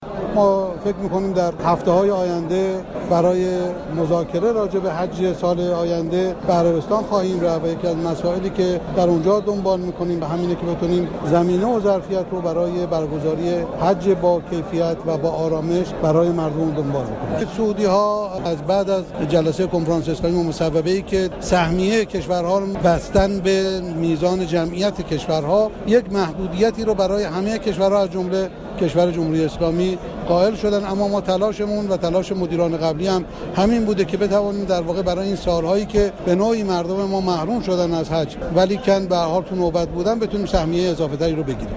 علیرضا رشیدیان رئیس جدید سازمان حج و زیارت در آئین معارفه خود از مذاكره با طرف سعودی برای افزایش ظرفیت حج تمتع خبر داد و گفت:هفته‌های آینده برای مذاكرات حج به عربستان خواهیم رفت و برای افزایش ظرفیت حج تلاش میكنیم .